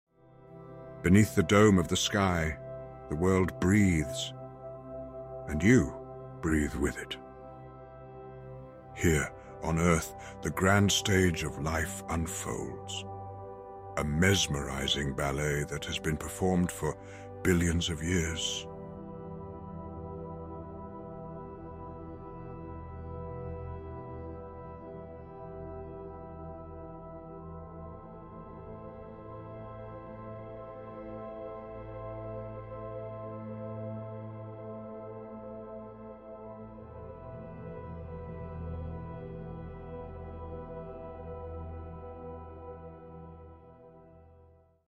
All voices are impersonations only and we are in no way associated with or represent the views of Ian McKellen, J. R. R. Tolkien, Tolkien Estate, Amazon or the LOTR franchise.